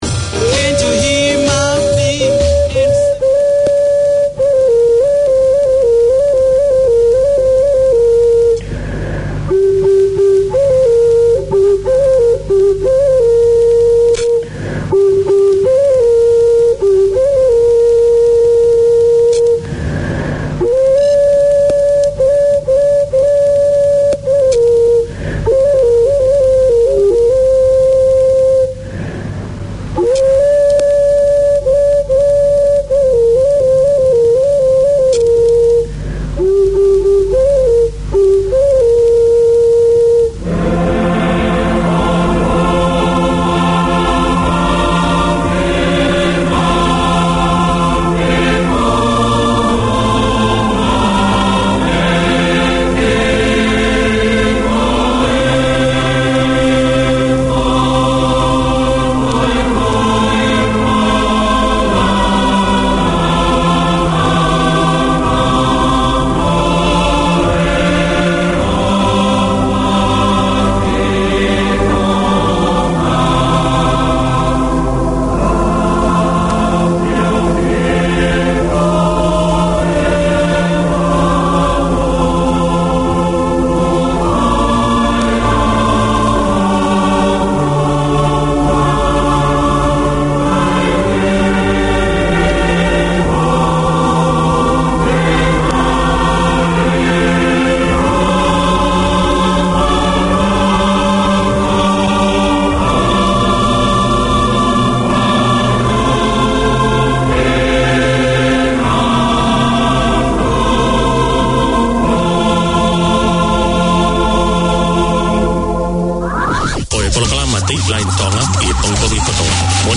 A new era in Tongan broadcasting, this is a talk show that focuses on community successes and debating issues from every angle relevant to Tongan wellbeing. Four mornings a week, the two hour programmes canvas current affairs of concern to Tongans and air in-depth interviews with Tongan figureheads, academics and successful Tongans from all walks of life.